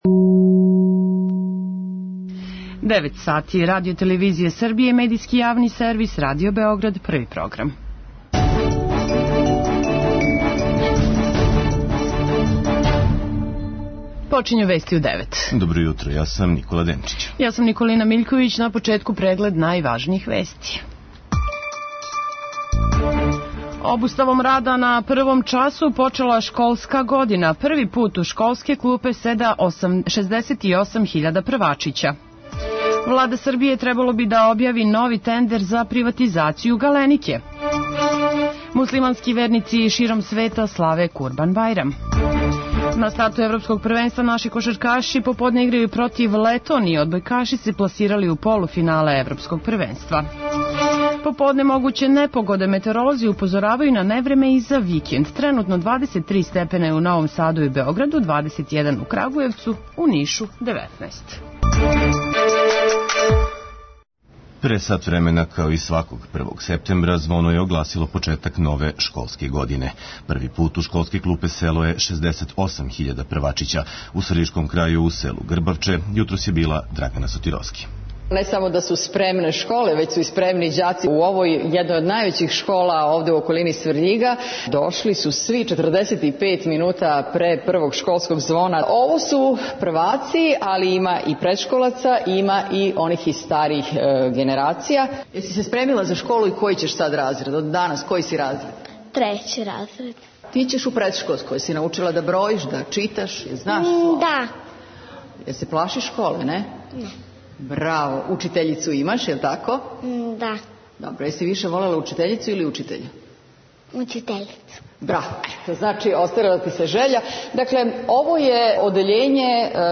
Вести у 9 | Радио Београд 1 | РТС